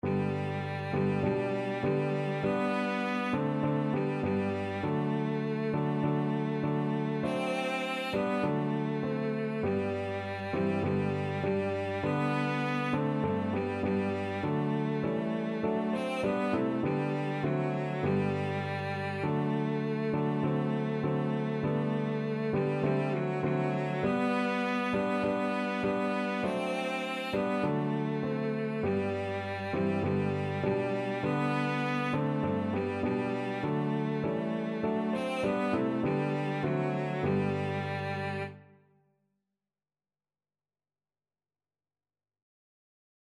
Christian
Classical (View more Classical Cello Music)